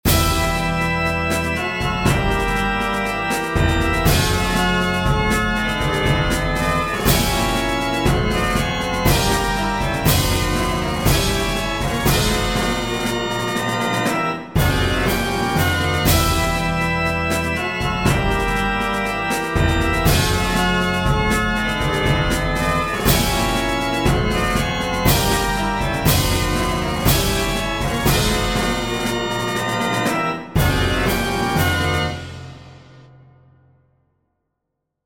Short 120bpm loop in 8edo
8edo_demo.mp3